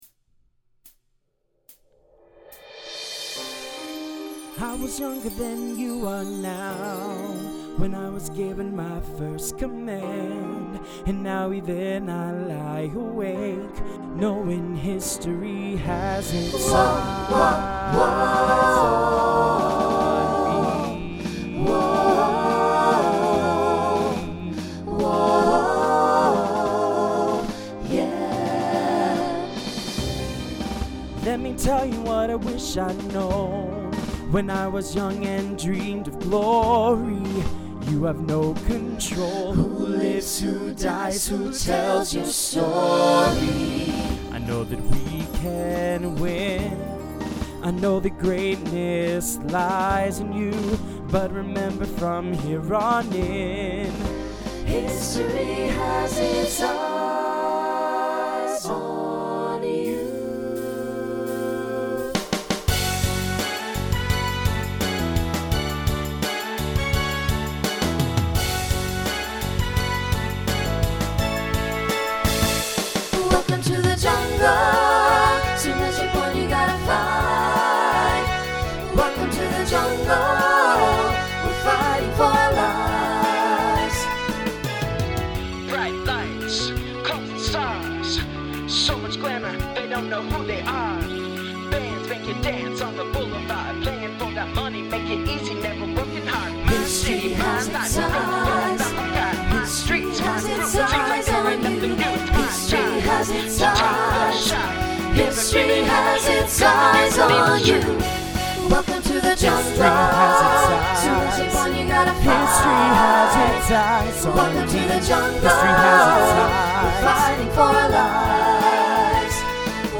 Voicing SATB Instrumental combo Genre Broadway/Film , Rock